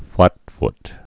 (flătft)